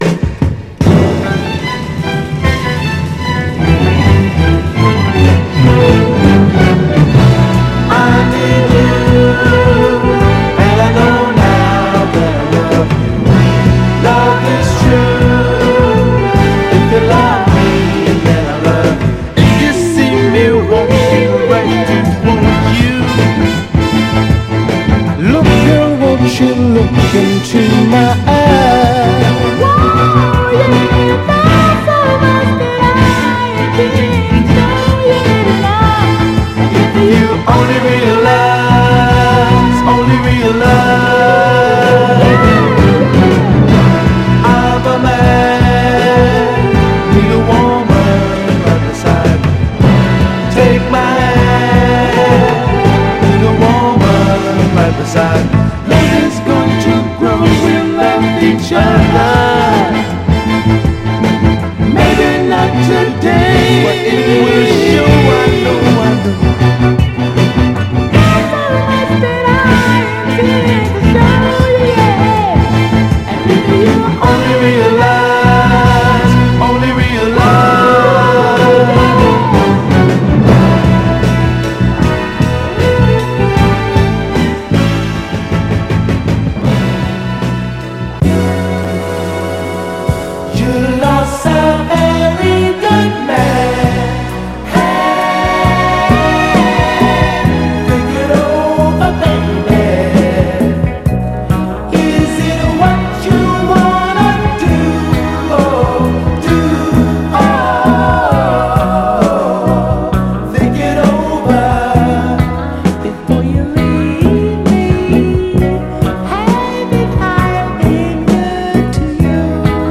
彼ららしい甘茶なスウィート・ソウルです！
ド派手なストリングスが効いたクロスオーヴァー・ミッド〜ノーザン・ファンク・ダンサー！
※試聴音源は実際にお送りする商品から録音したものです※